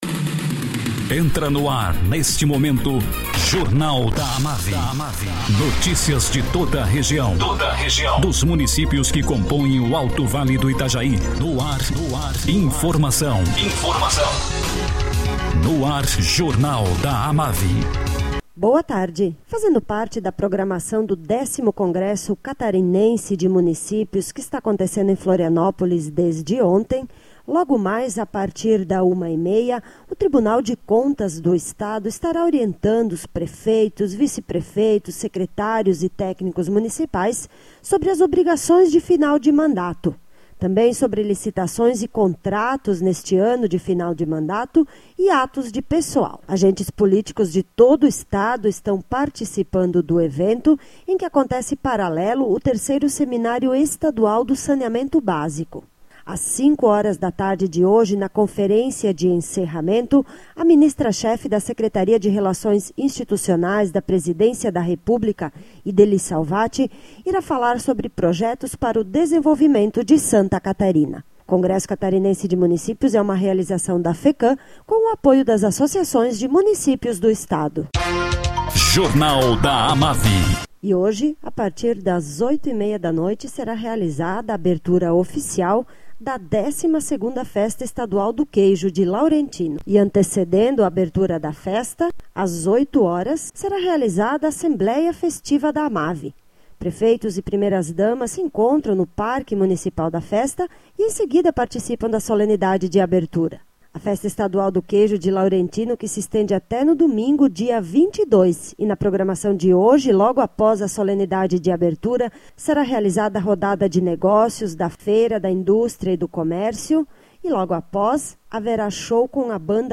Acervo de boletins